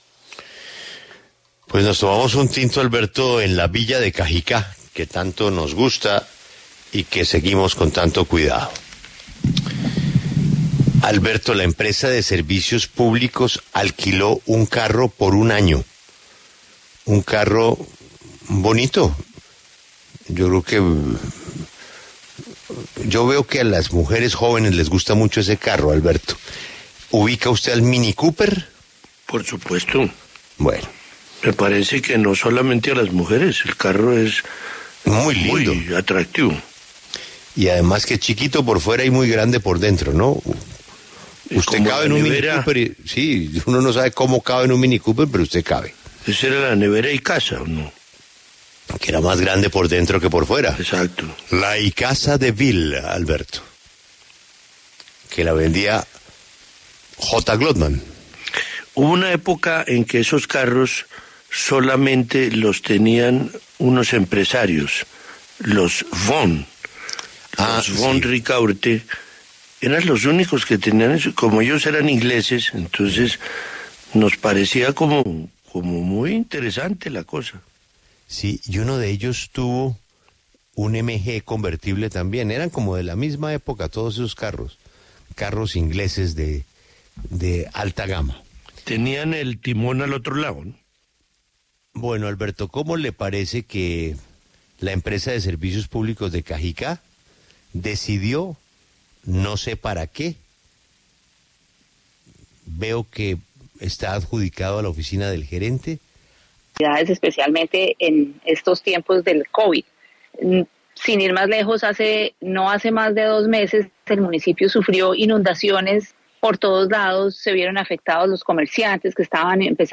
En diálogo con La W, Tita Cavelier, concejal del municipio de Cajicá, cuestionó la pertinencia del contrato, debido a que en los últimos meses se presentaron inundaciones que pusieron en riesgo a la comunidad.